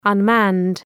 unmanned.mp3